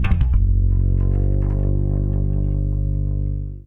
Bass_Stab_08.wav